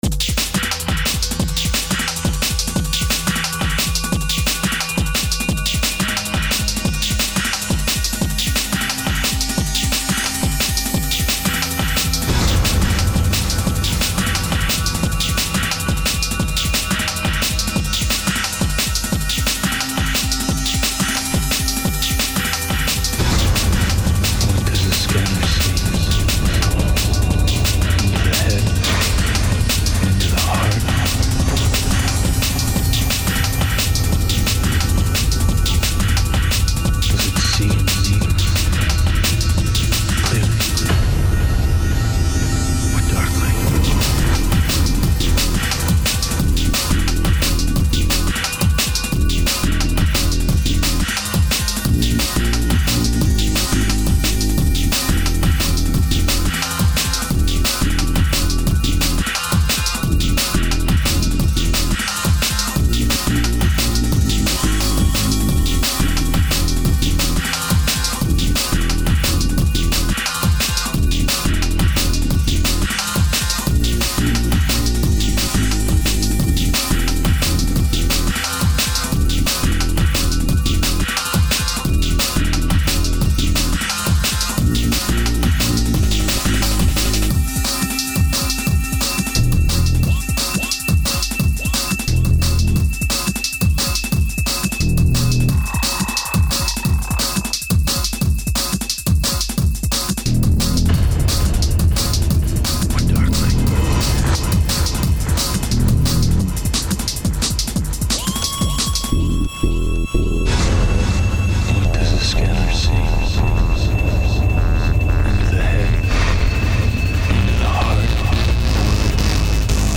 Dark drum and bass tune